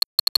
NOTIFICATION_8bit_09_mono.wav